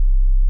Below are some wave files of sine waves generated using very short lookup tables, each file is 45k.
32 point LUT, linear interpolation